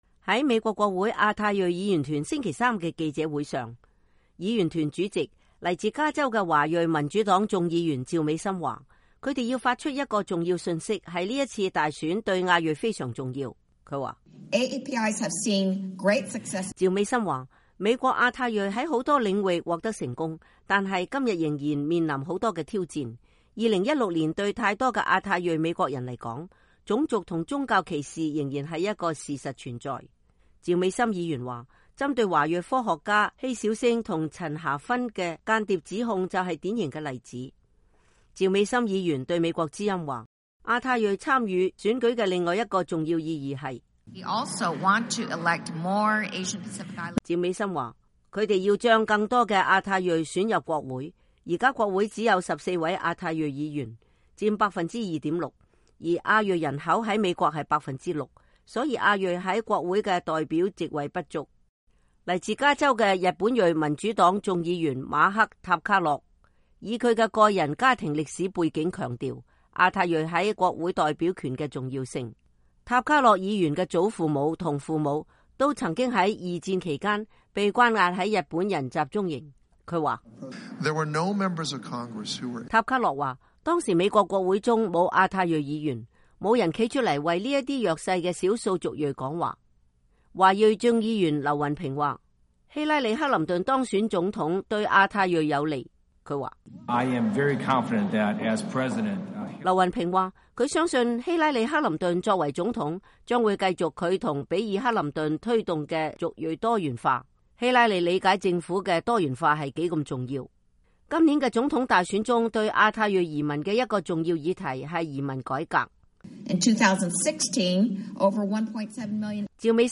在美國國會亞太裔議員團星期三的記者會上，議員團主席、來自加州的華裔民主黨眾議員趙美心說，他們要發出的一個重要信息是這次大選對亞太裔非常重要。